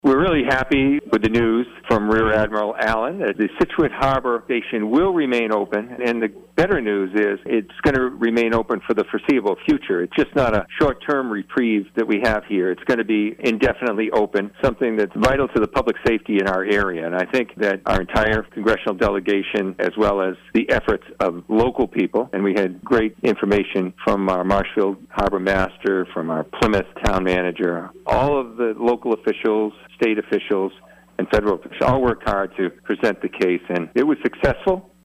Congressman Bill Keating tells WATD News that certain arguments in particular resonated with Coast Guard leadership.